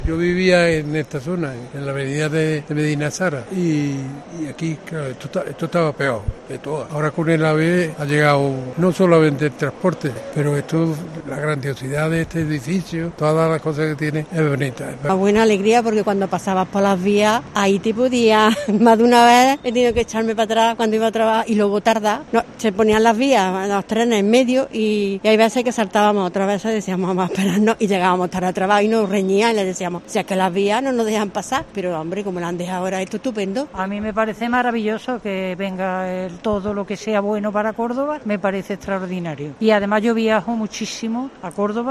Escucha a vecinos de la zona de Renfe en Córdoba que han vivido el cambio con la llegada del AVE hace 30 años